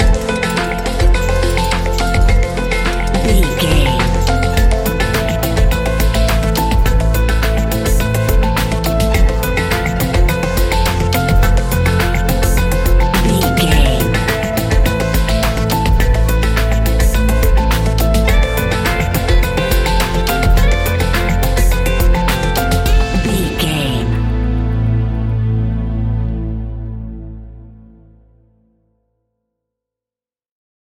Ionian/Major
electronic
techno
trance
synthesizer
synthwave